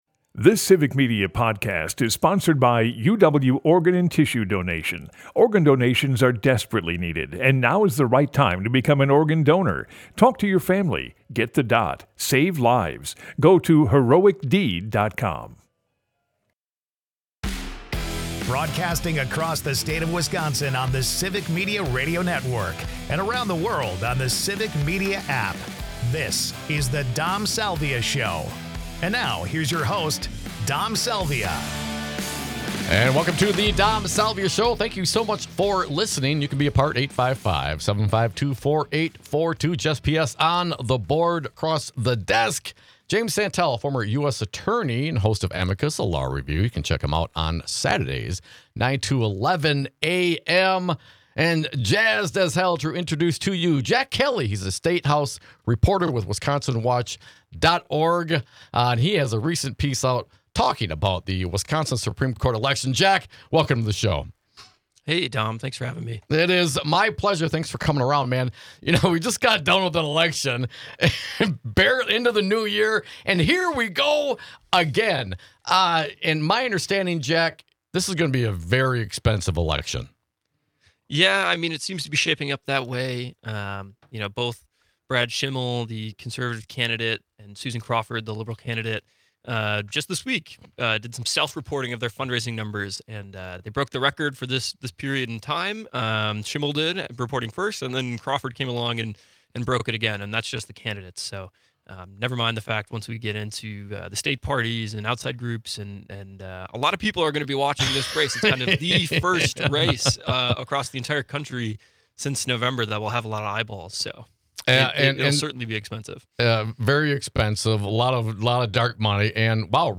Jim Santelle, former US Attorney and host of Civic Media's Saturday show Amicus: A Law Review joins us as cohost. This hour, it's all about the Supremes- that is, the Supreme Courts of Wisconsin and the nation.